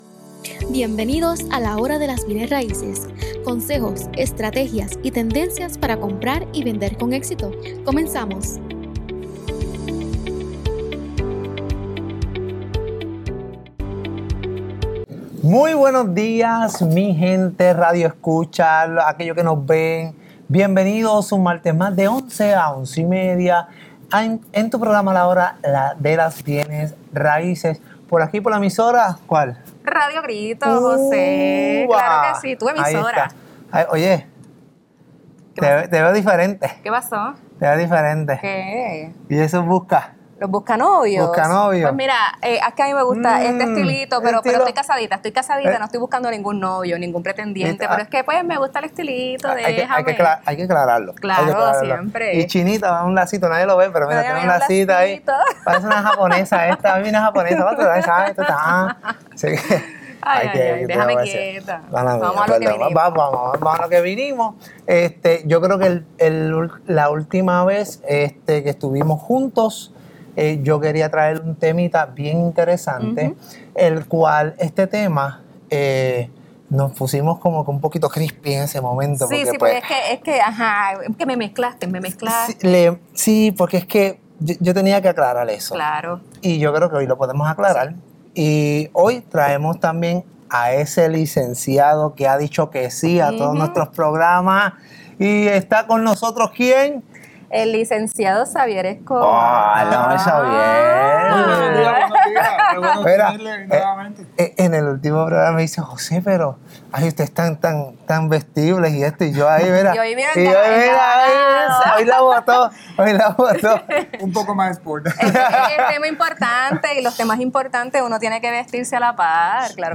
Para aclarar estas y otras dudas, hoy conversamos con un notario experto que nos orientará sobre los aspectos legales y prácticos de este acuerdo, cada vez más común en el mercado inmobiliario local.